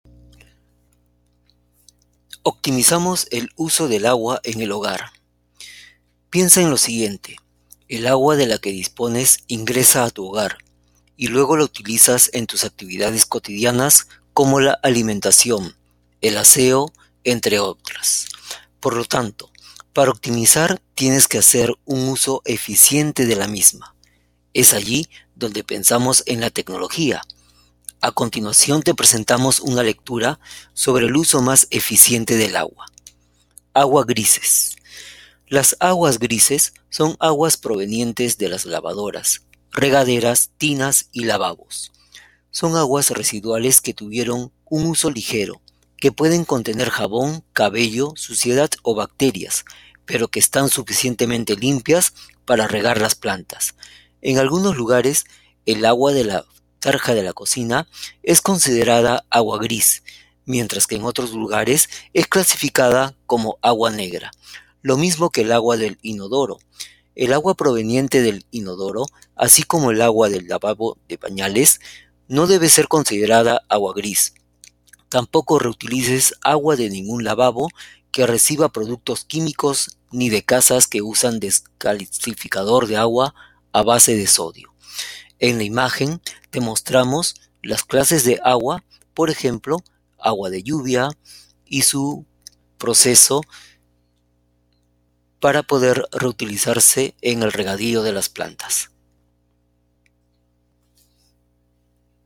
AQUI el audio de la lectura para optimizar el uso del agua en el hogar: